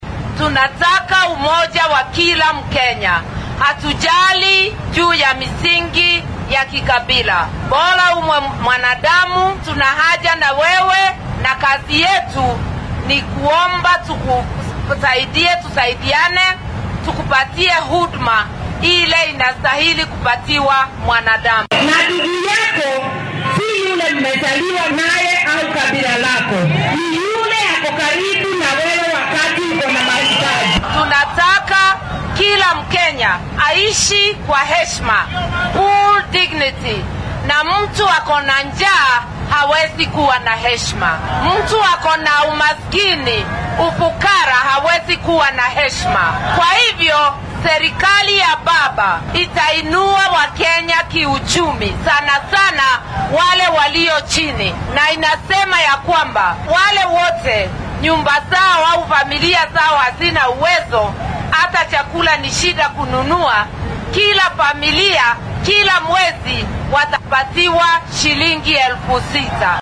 Ku xigeenka musharraxa madaxweyne ee isbeheysiga Azimio La Umoja-One Kenya , Martha Karua ayaa shalay isku soo bax siyaasadeed ku qabatay magaalada Eldoret ee ismaamulka Uasin Gishu.